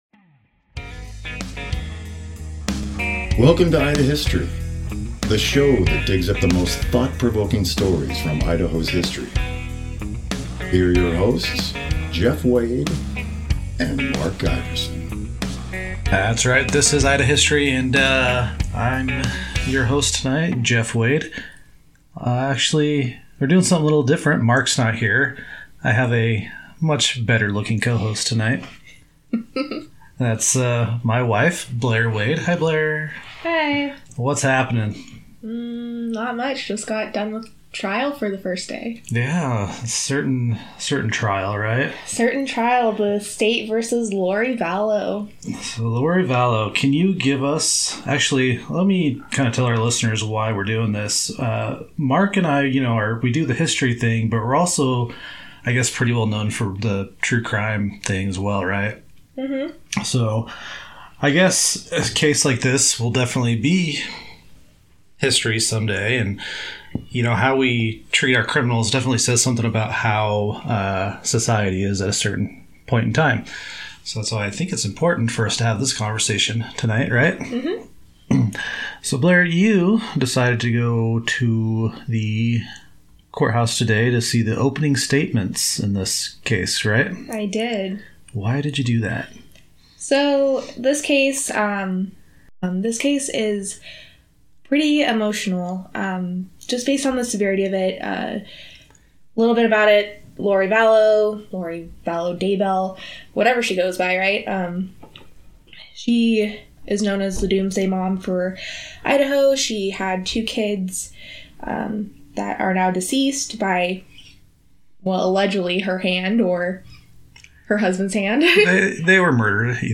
Today, it finally ended up in court and we are lucky enough to be able to interview someone who was in that court room. We talk about how it all went down.